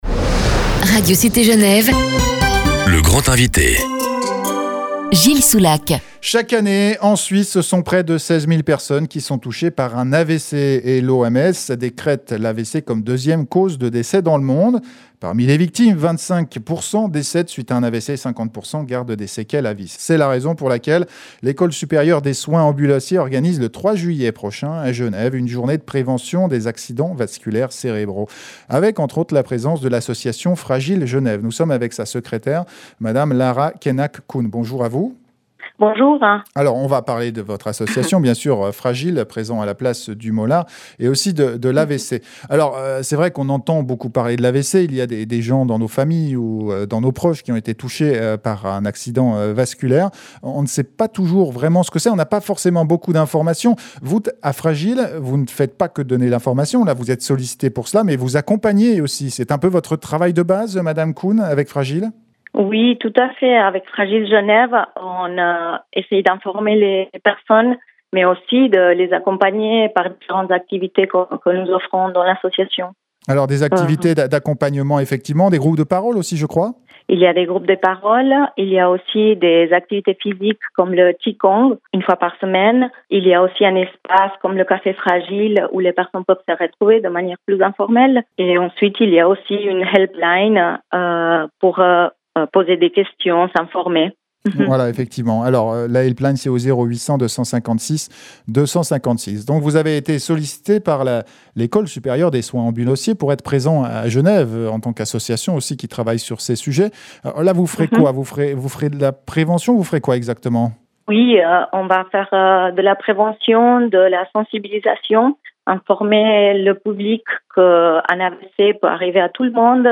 L'interview est diffusée dans l'émission "Le Grand Invité" du 28 juin 2019, de Radio Cité Genève, à 8h10, 11h30 et 16h40 (durée: 5min35)